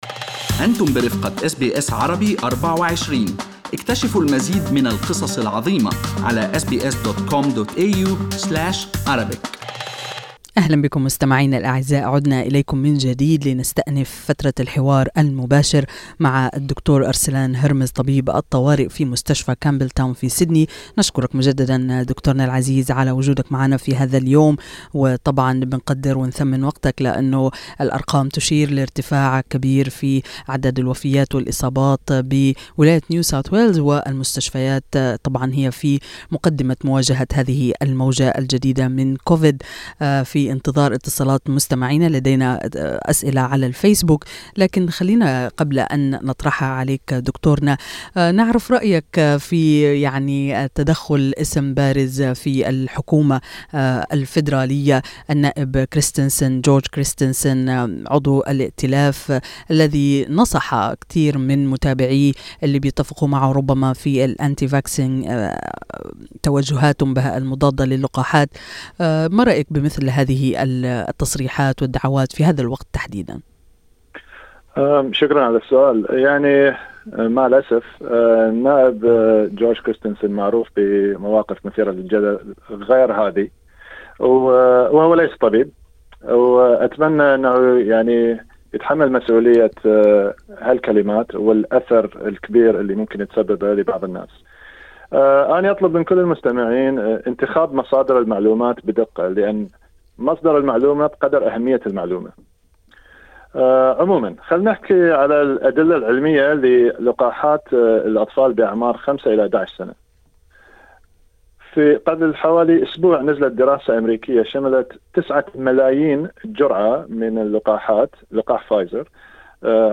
طبيب أخصائي يجيب على الأسئلة الشائعة بخصوص كوفيد-19 واللقاحات والجرعات المعززة